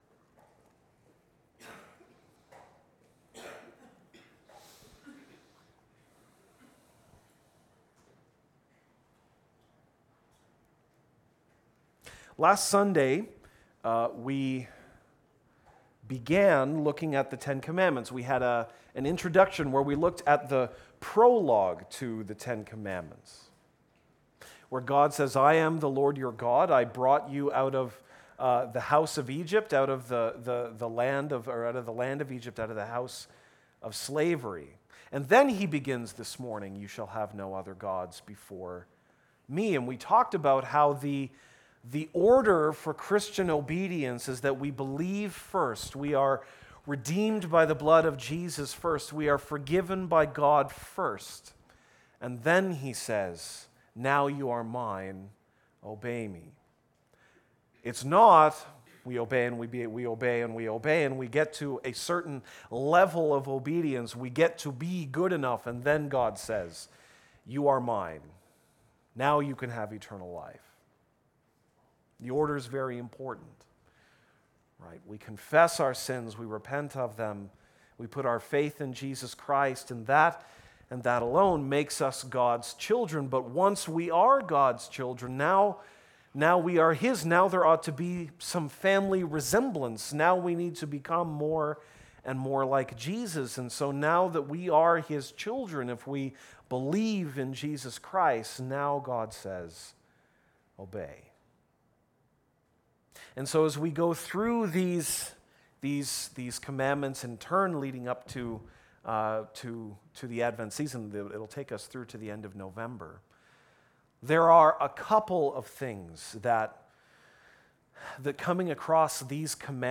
August 6, 2017 (Sunday Morning)